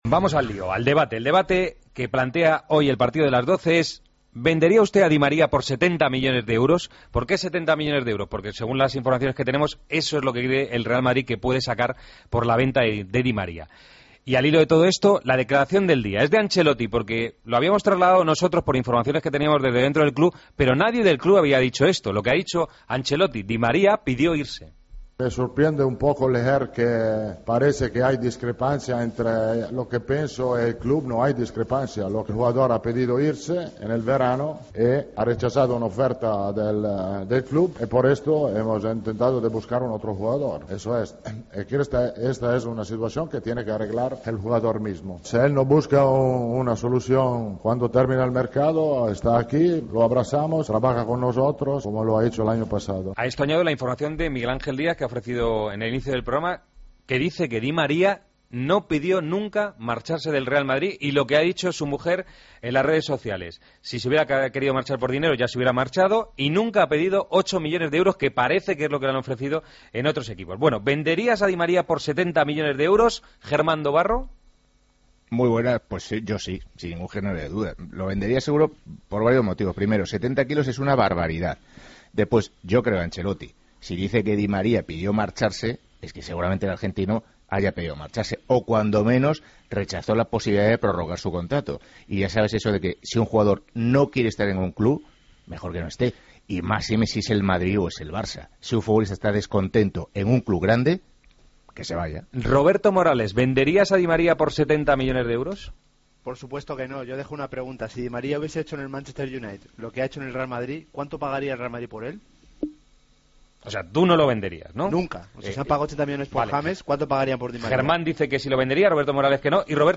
El debate de los jueves: ¿Venderías a Di María por 70 millones de euros?